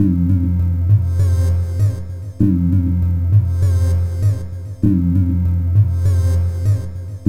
UFO_Energy.L.wav